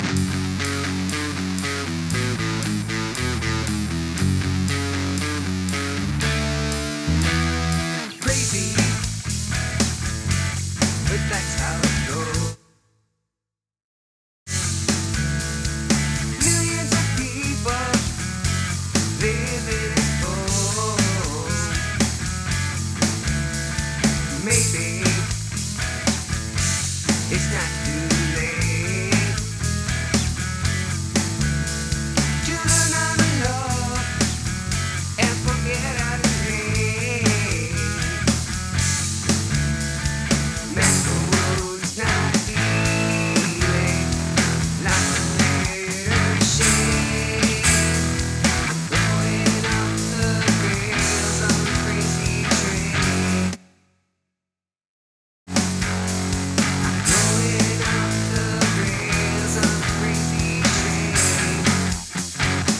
There are 2 P420 voices not in time perfectly so they sound phased. 1 bass. 2 guitars verse and lead. Akai MPD218 tappy drums.
The song has a lot going on in it.